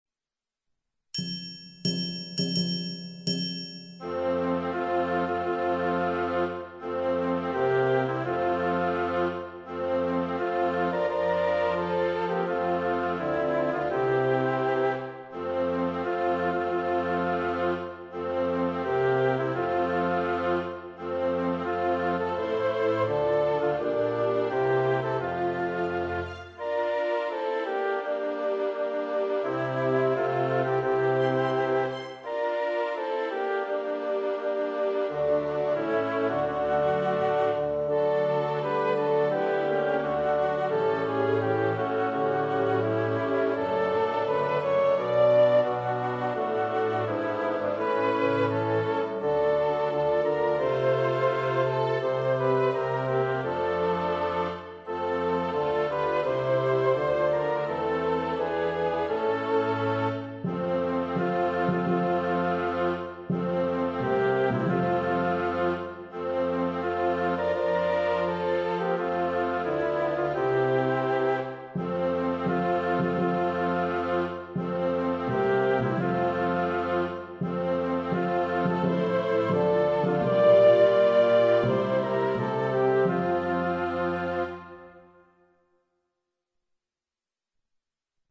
E' un brano molto famoso, tratto dal Lohengrin. Lo proponiamo in versione didattica per flauto.